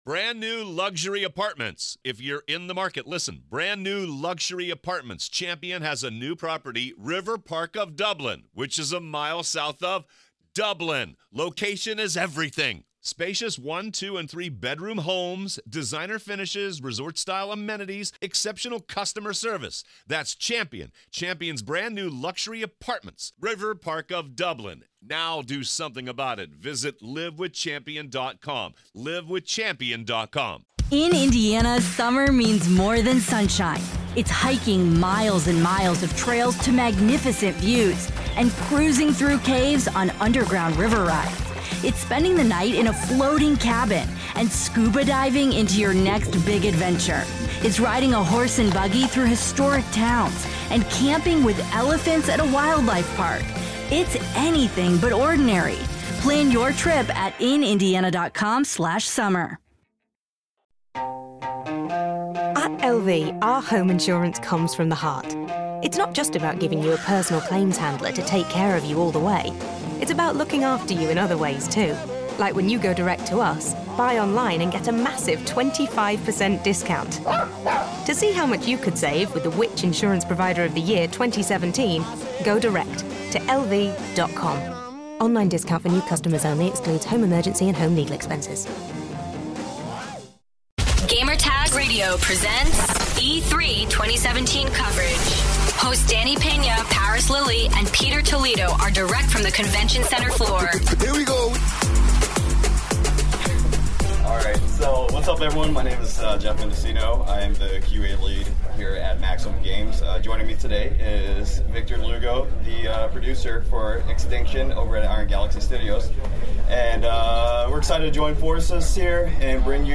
E3 2017: Extinction Presentation